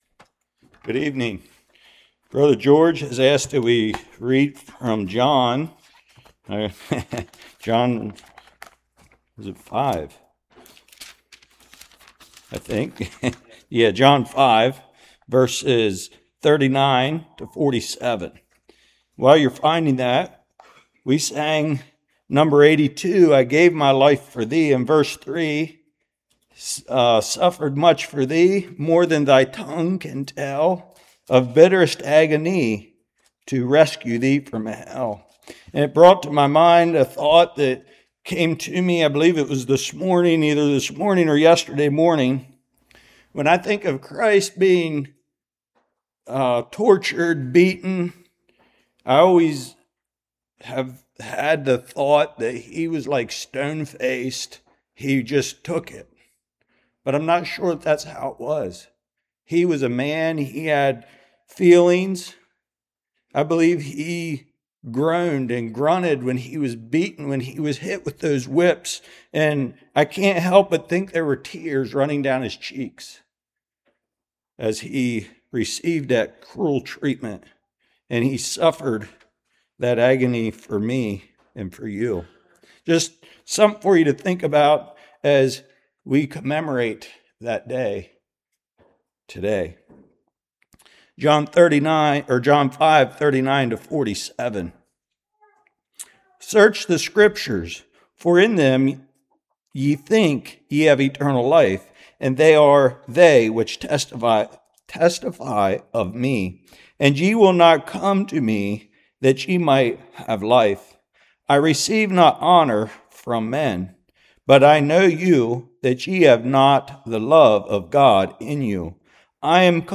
Service Type: Good Friday